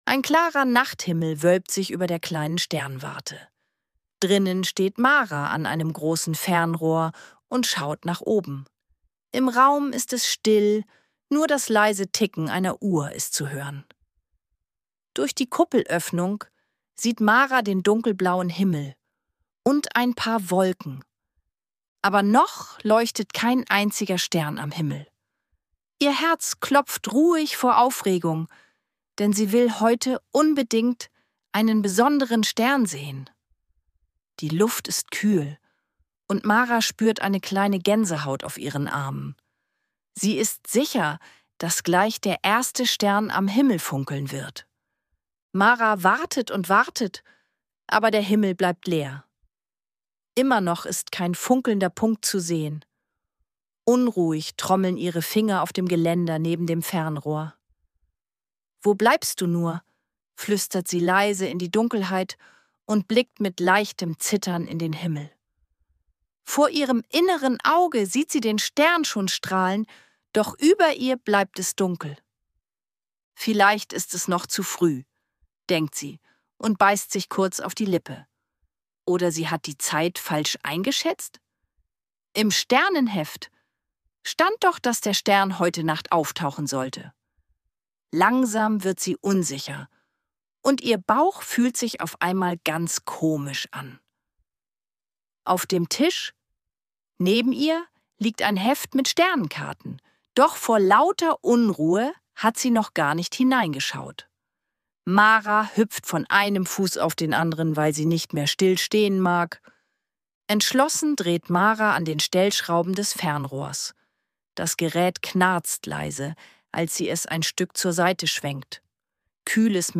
Ruhige Kindergeschichten zum Anhören